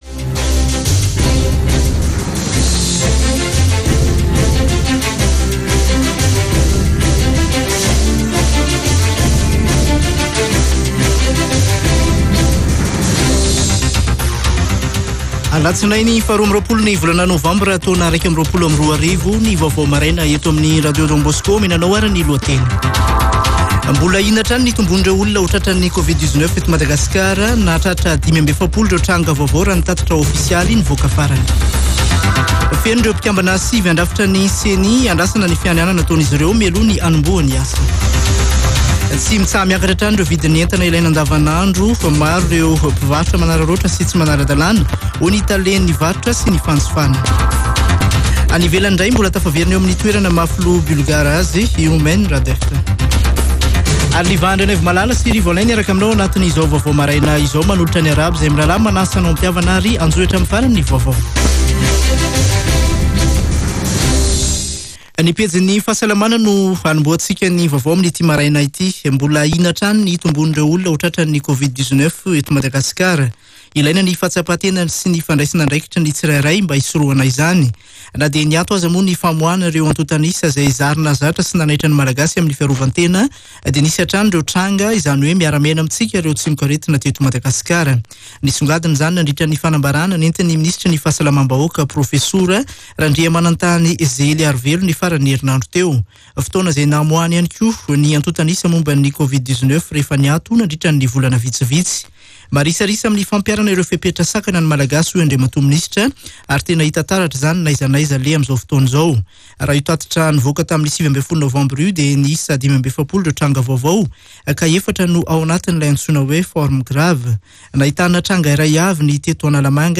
[Vaovao maraina] Alatsinainy 22 novambra 2021